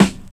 18 SNARE  -L.wav